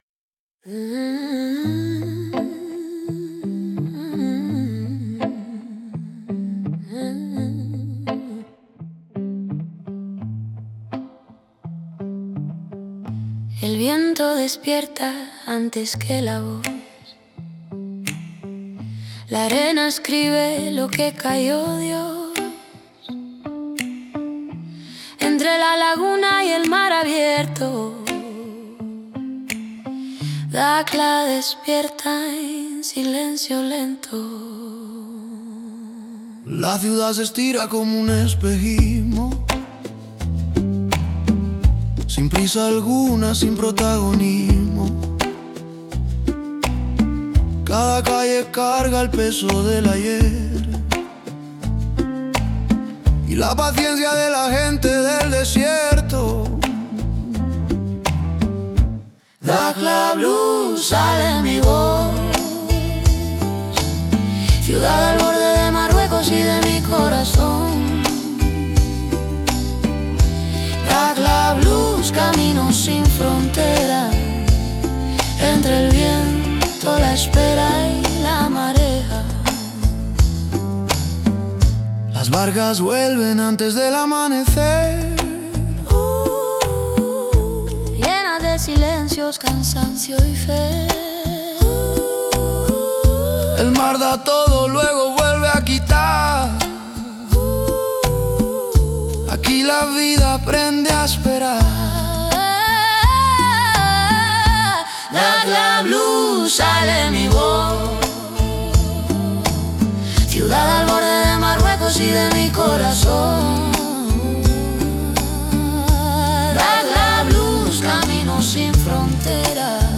Clip : Dakhla Blues Dakhla Blues est un poème-chant qui explore une ville-frontière, suspendue entre désert, lagune et océan.
Le refrain, récurrent, ancre une identité intime et universelle à la fois, un blues salin fait d’espoir mesuré et de lucidité.